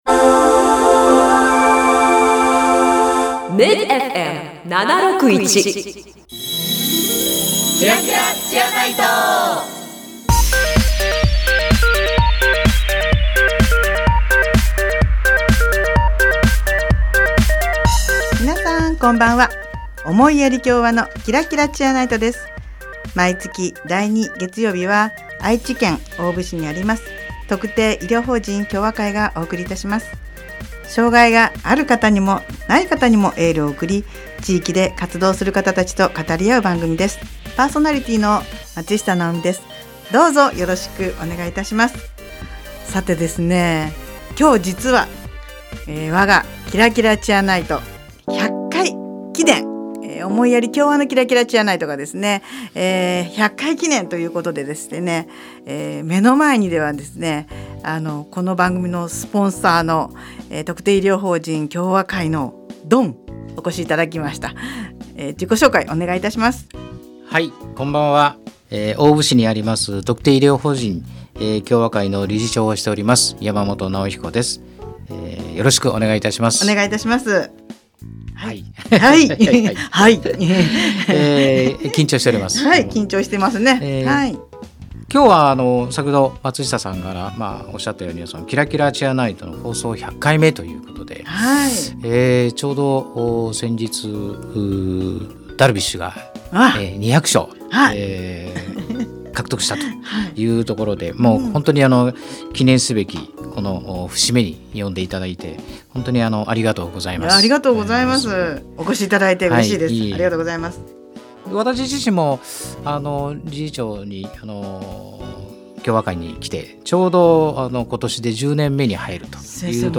【放送時間 】第2月曜日 19：00 MID-FM 76.1
この番組では、地域の医療・福祉に携わる方々と語り合い、偏見にさらされやすい障がいのある方に心からのエールを送ります。 毎回、医療・福祉の現場に直接携わる方などをゲストに迎え、現場での色々な取り組みや将来の夢なども語り合います。